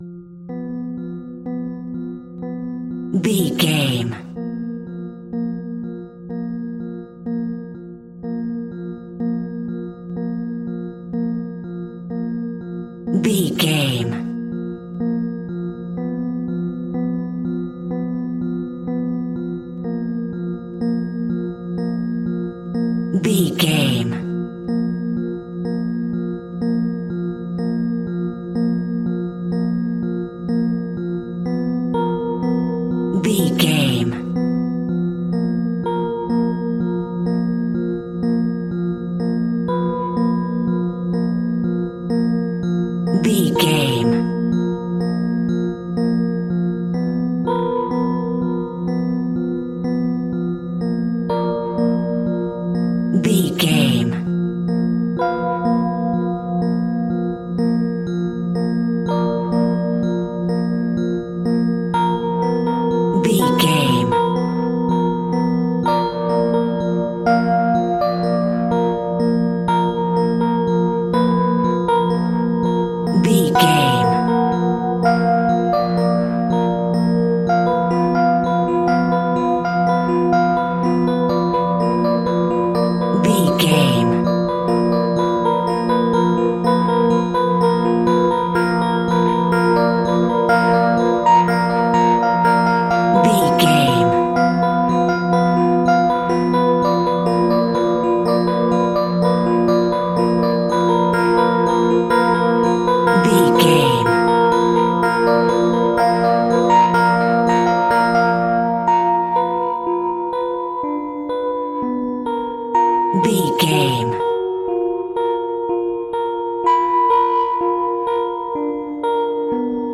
Scary Electric Piano Sounds.
In-crescendo
Diminished
B♭
ominous
haunting
eerie
horror